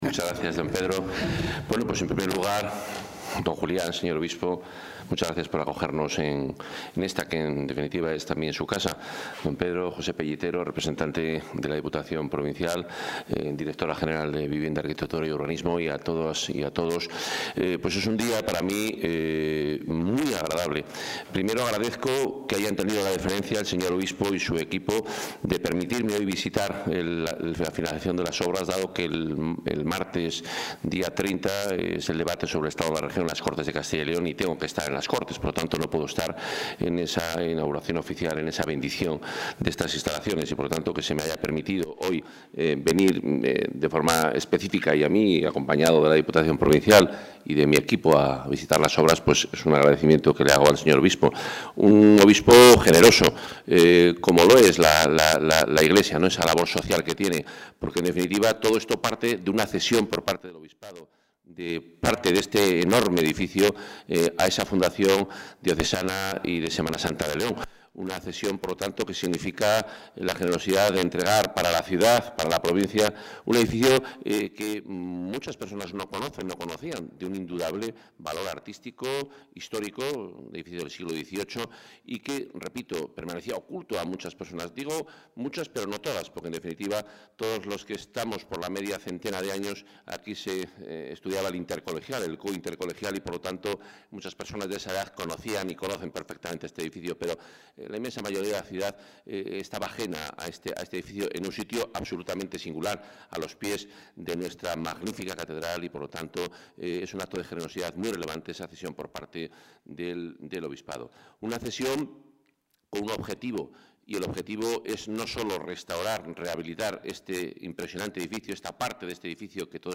Declaraciones del consejero de Fomento y Medio Ambiente.